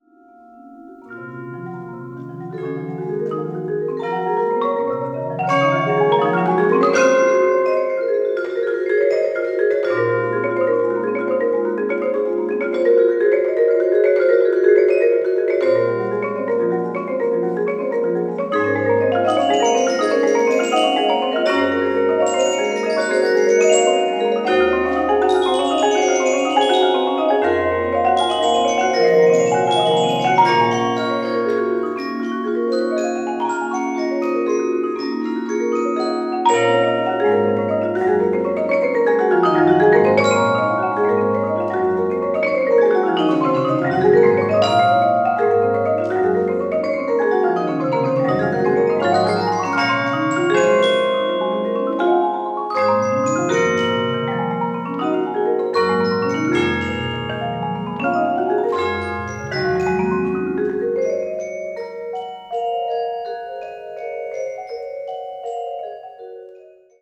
Tetrahedral Ambisonic Microphone
Recorded February 23, 2010, in the Bates Recital Hall at the Butler School of Music of the University of Texas at Austin.